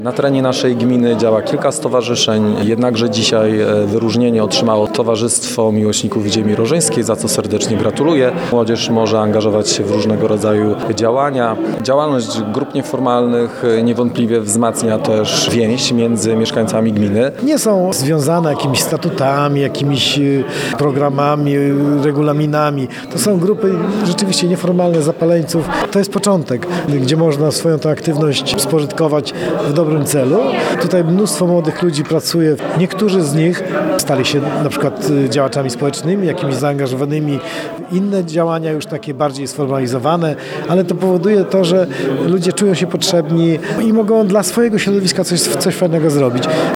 – Działalność grup nieformalnych jest niezwykle potrzebna w lokalnej społeczności – zgodnie mówią Rafał Wilczewski, wójt gminy Prostki i Marek Chojnowski, starosta powiatu ełckiego.